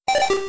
pokegear_off.wav